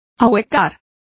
Complete with pronunciation of the translation of hollowing.